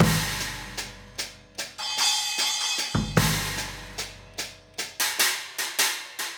Hi Hat and Kick 04.wav